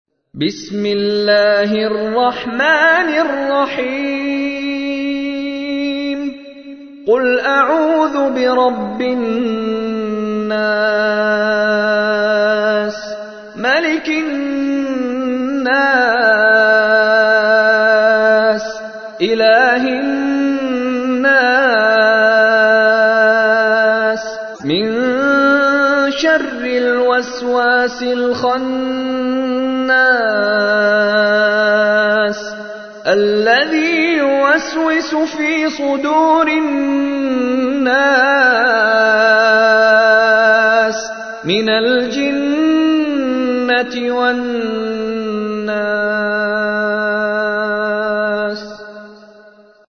تحميل : 114. سورة الناس / القارئ مشاري راشد العفاسي / القرآن الكريم / موقع يا حسين